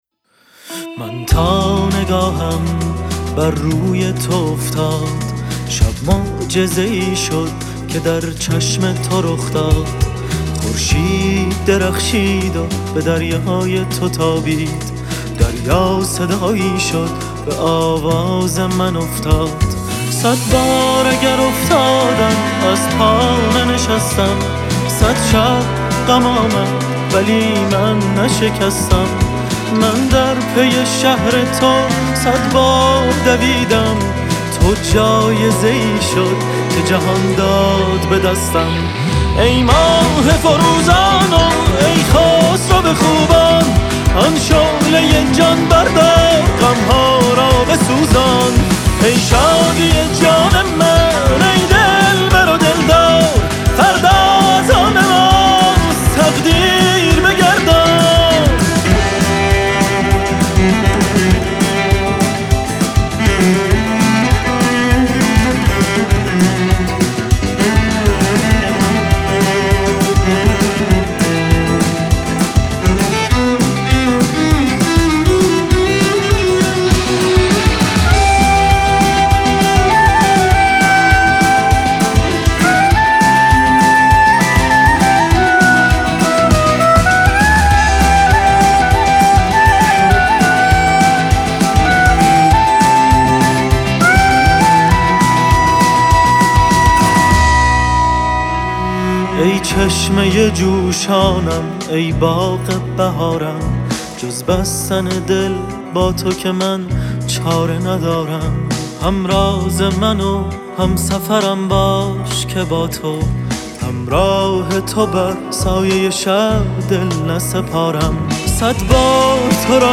آهنگ تیتراژ